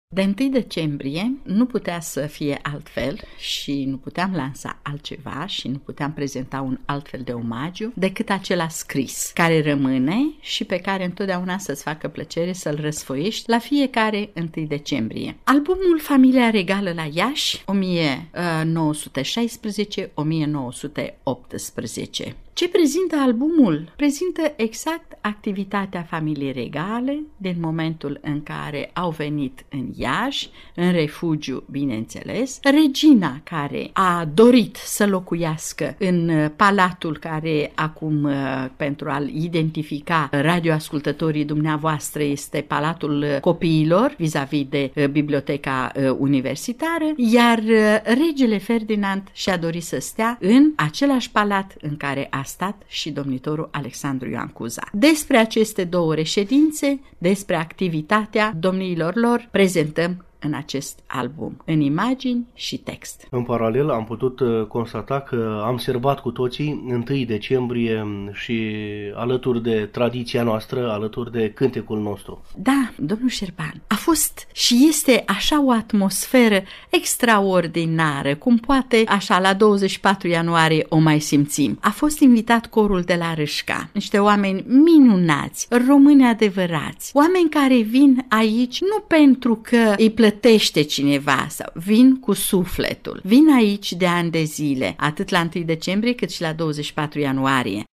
Plini de emoție, pe fundalul unirii, dialogăm cu câțiva membri ai Grupului Vocal Bărbătesc Flori de Măr din Râșca, județul Suceava, pe care i-am întâlnit, la Iași, în incinta Muzeului Unirii, de ziua națională a României, care au venit cu sufletele deschise să încălzească sufletele românilor, combinând sărbătoarea cu tradiția, intrepretând cântece dedicate, din inimă, celor care au luptat pentru glia străbună.